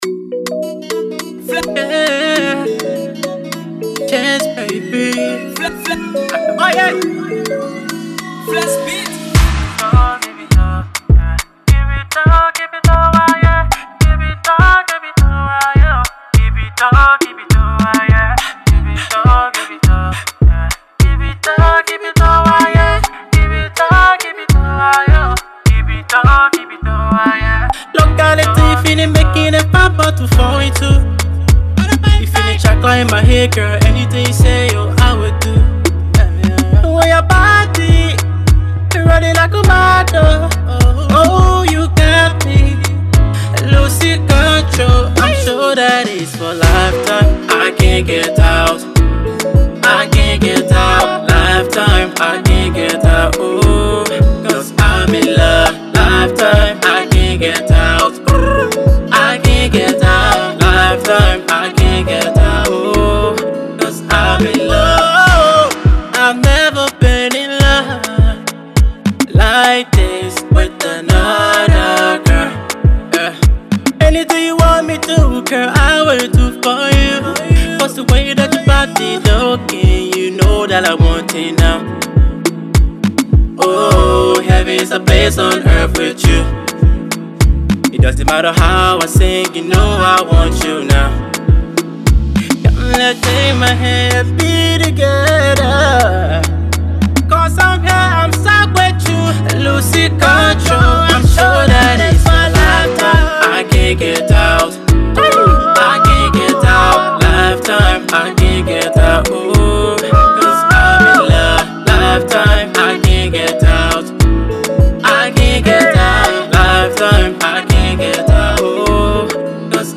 Afro-Pop